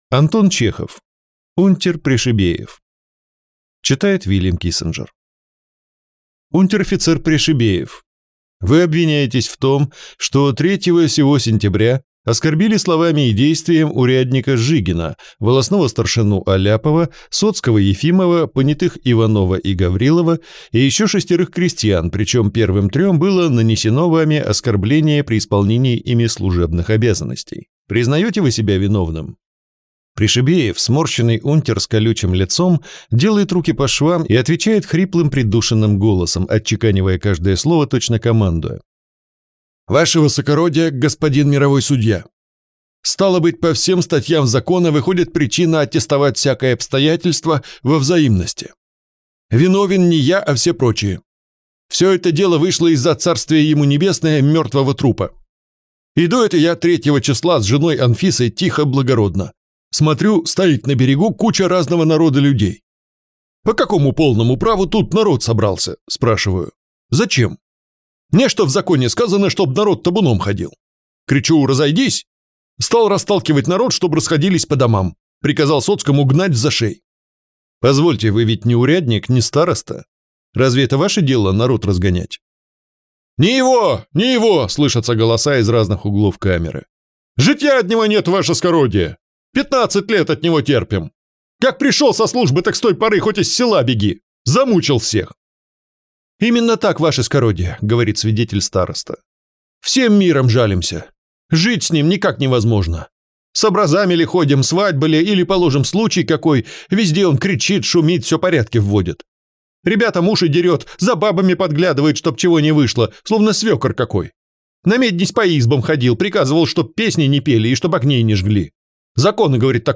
Аудиокнига Унтер Пришибеев | Библиотека аудиокниг